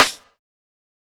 TC2 Snare 28.wav